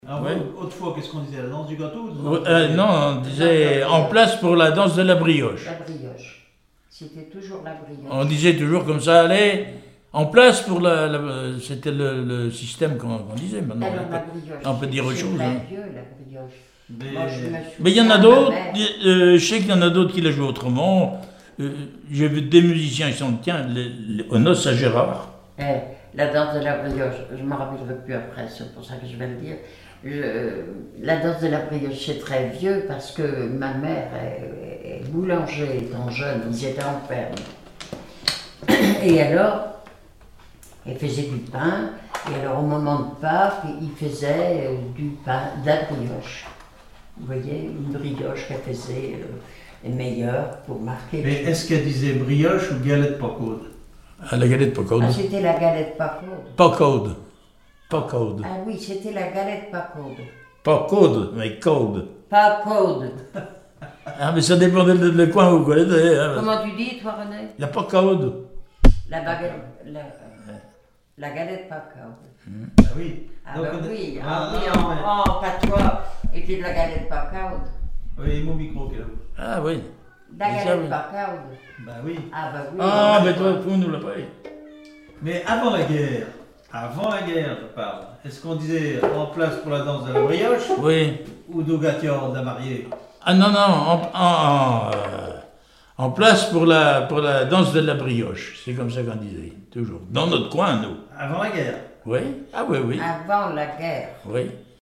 Témoignages et musiques